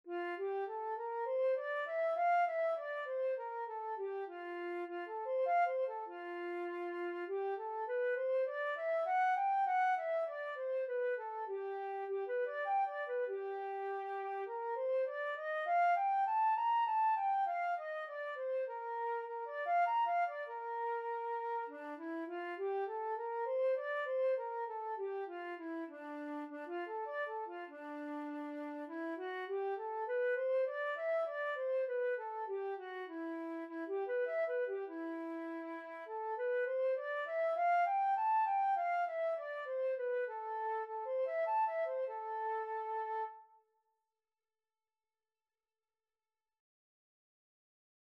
Flute scales and arpeggios - Grade 2
F major (Sounding Pitch) (View more F major Music for Flute )
4/4 (View more 4/4 Music)
D5-Bb6
flute_scale_grade2.mp3